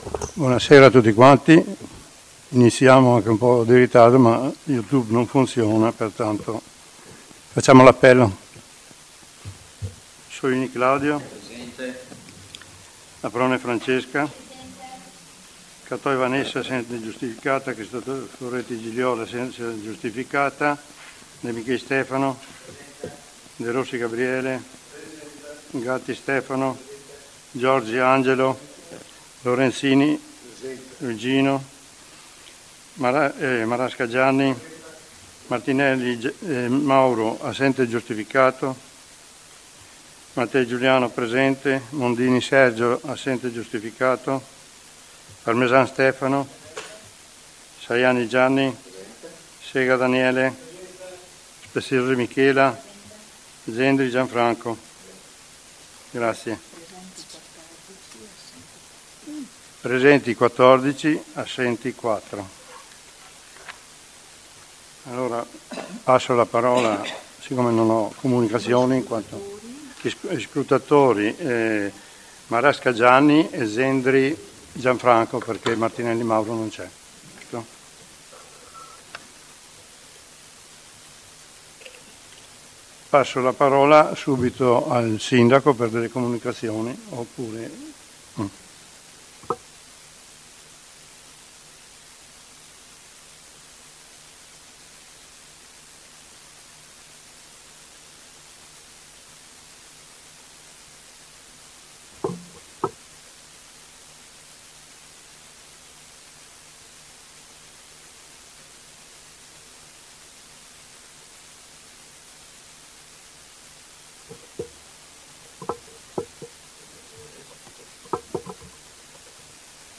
AudioSedutaConsiglio.mp3